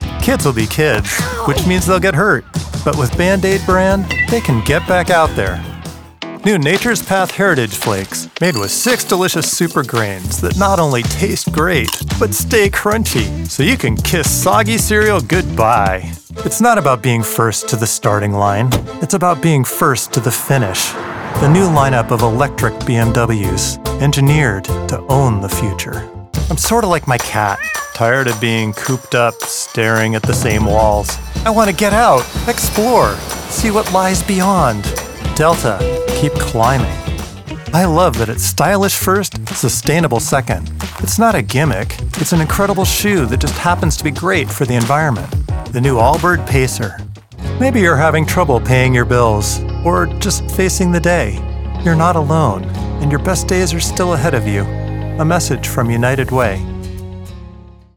COMMERCIAL DEMO REEL
Commercial samples: Band-Aid, Nature’s Path, BMW Electric, Delta Airlines, AllBirds, United Way
• Quality: professional, ready-to-use recordings with minimum fuss from a broadcast-quality home studio
• Warmth: “a breathy, earthy, folky quality & texture … incredibly approachable”
• Confidence: “soothing yet authoritative and credible”